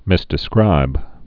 (mĭsdĭ-skrīb)